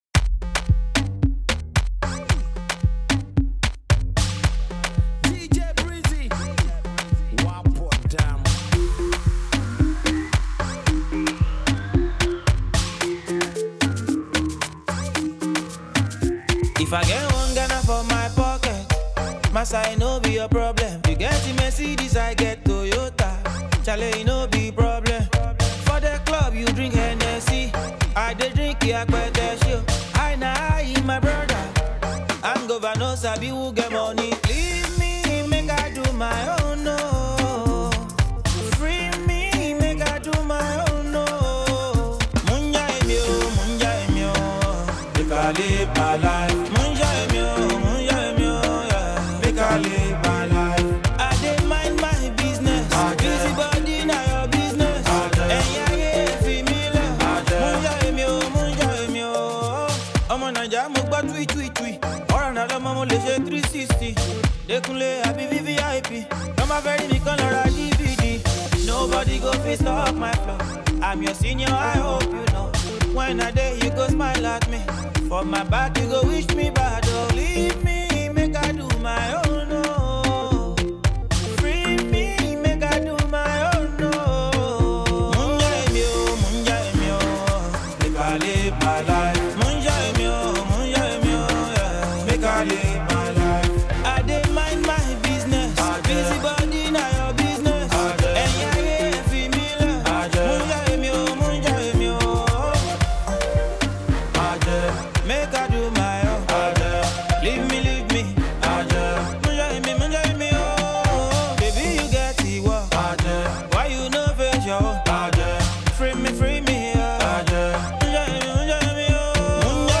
catchy record
feel-good track